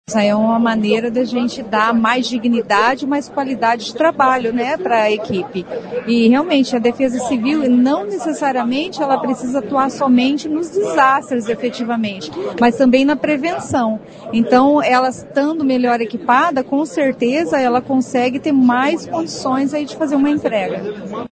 A vice-prefeita Sandra Jacovós diz que a Defesa Civil precisa estar bem equipada também para a prevenção de catástrofes.